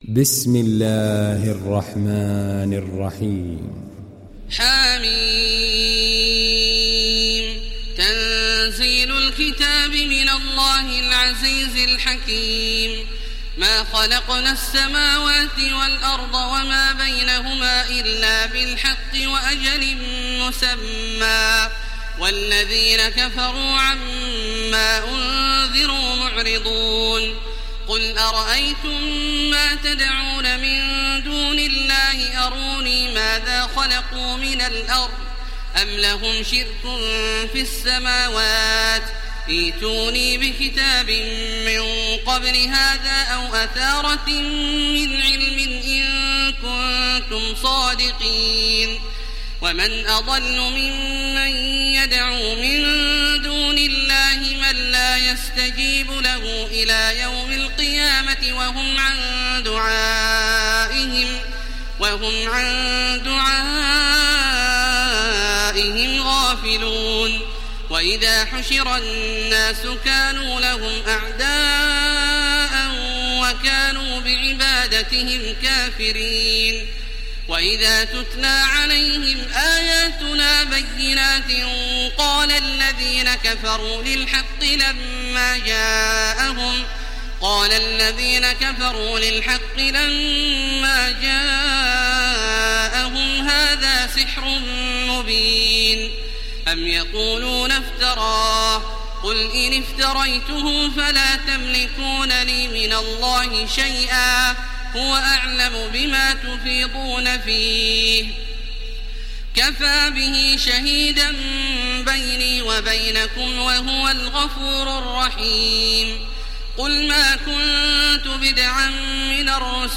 Download Surah Al Ahqaf Taraweeh Makkah 1430